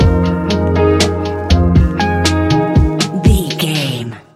Ionian/Major
F♯
chilled
laid back
Lounge
sparse
new age
chilled electronica
ambient
atmospheric
instrumentals